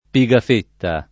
[ pi g af % tta ]